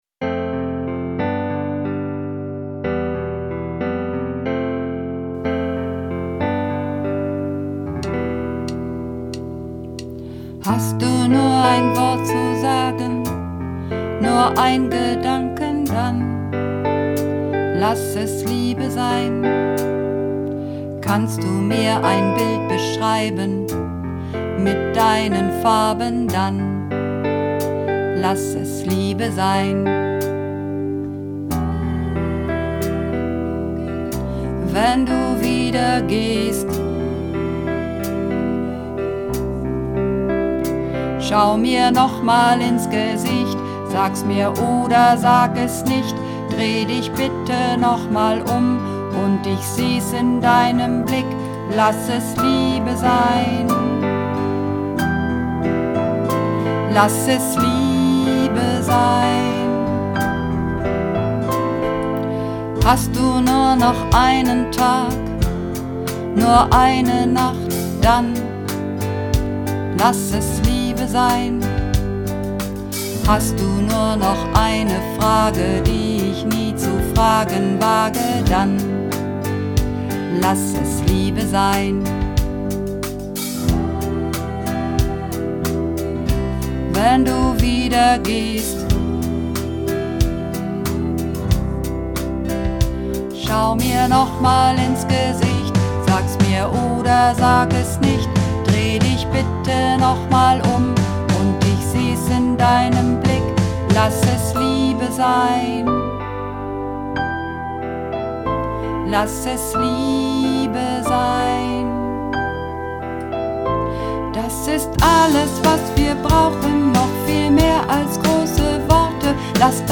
Männer